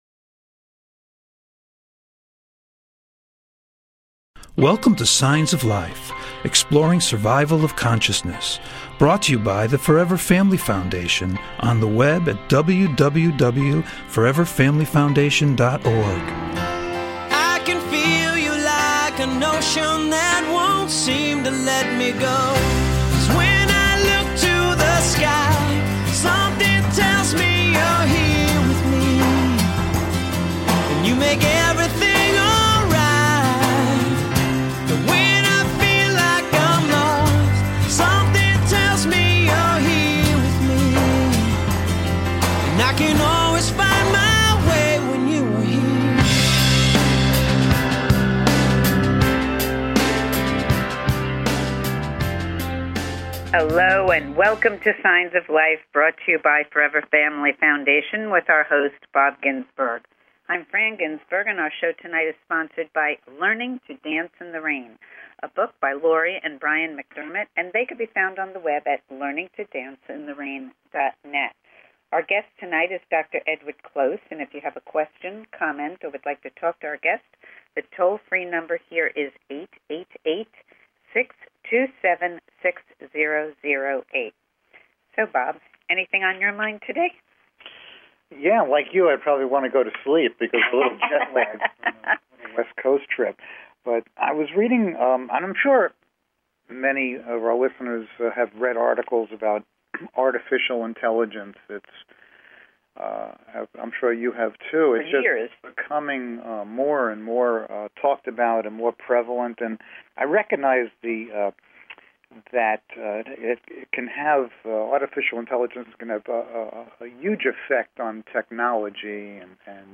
Interviewing guest